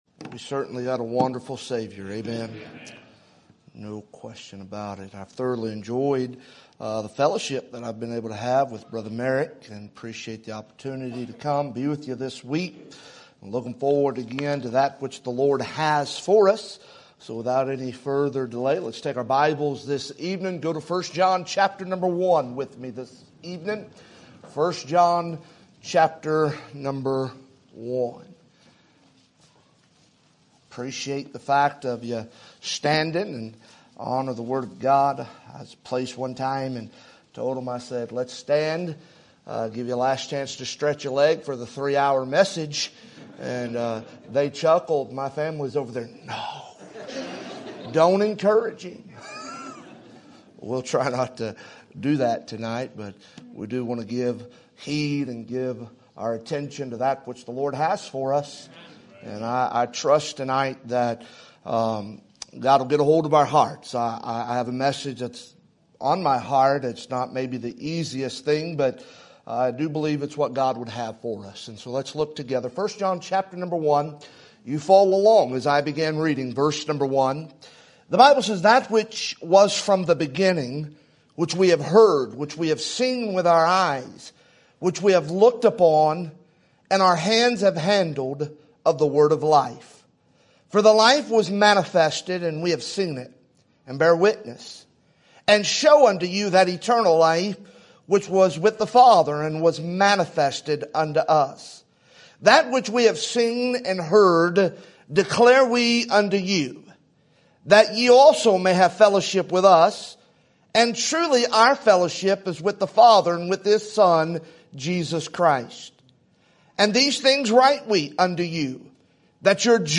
Sermon Topic: Spring Revival Sermon Type: Special Sermon Audio: Sermon download: Download (22.43 MB) Sermon Tags: Church Revival Confess Sins